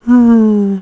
c_zombim3_atk2.wav